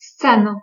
Ääntäminen
Vaihtoehtoiset kirjoitusmuodot (vanhahtava) scæne Ääntäminen : IPA : /siːn/ US : IPA : [siːn] Lyhenteet ja supistumat sc.